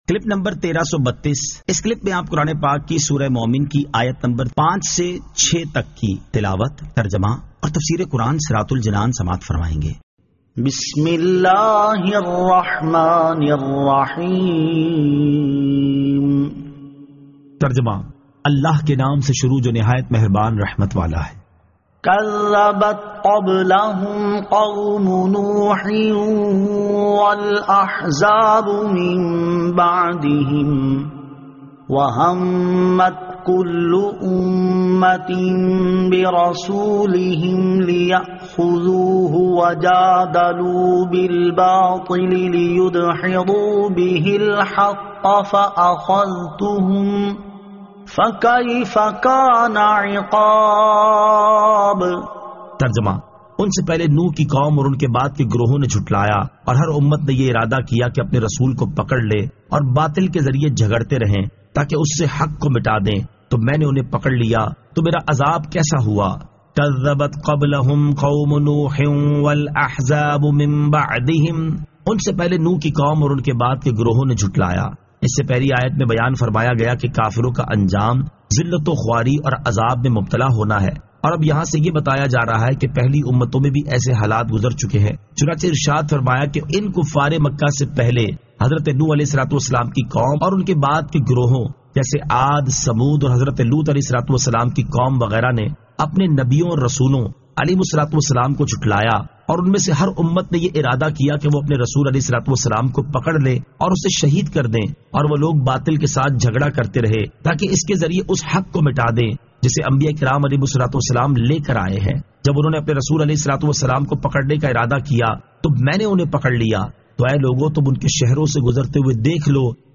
Surah Al-Mu'min 05 To 06 Tilawat , Tarjama , Tafseer